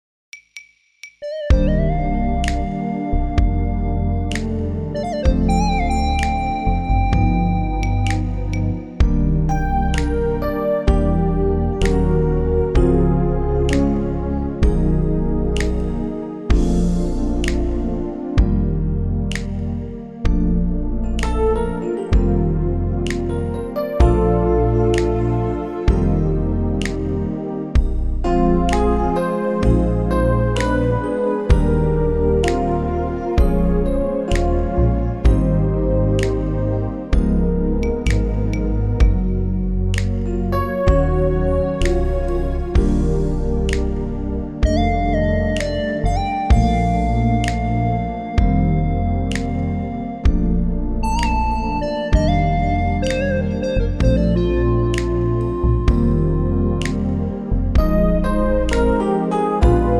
Unique Backing Tracks
key - Bb - vocal range - Bb to C
Super smooth arrangement